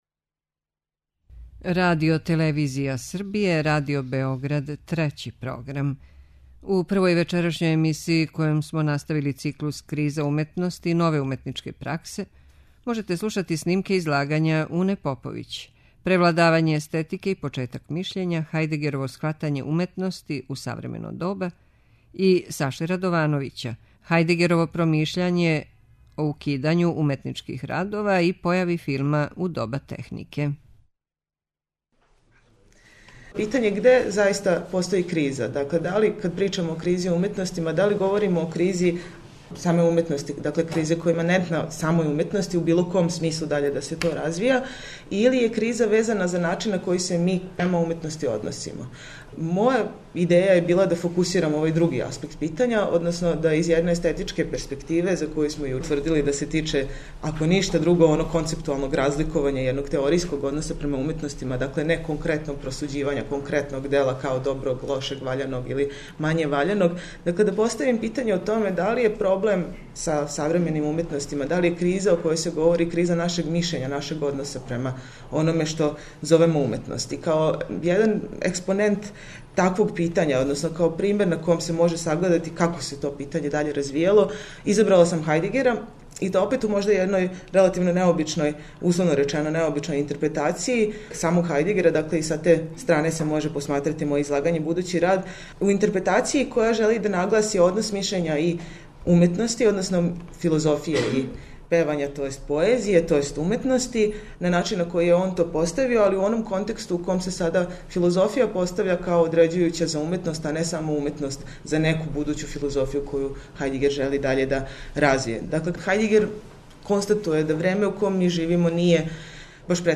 У две емисије, којима настављамо циклус КРИЗА УМЕТНОСТИ И НОВЕ УМЕТНИЧКЕ ПРАКСЕ, можете пратити снимке излагања са истоименог научног скупа одржаног средином децембра у организацији Естетичког друштва Србије.
Научни скупови